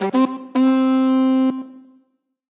Minecraft Version Minecraft Version snapshot Latest Release | Latest Snapshot snapshot / assets / minecraft / sounds / item / goat_horn / call1.ogg Compare With Compare With Latest Release | Latest Snapshot